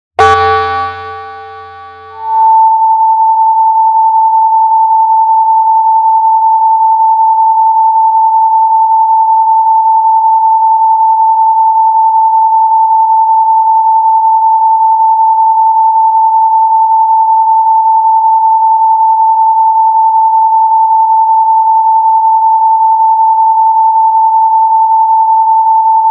It’s a frequency. 888Hz unlocks abundance, flow + mental clarity.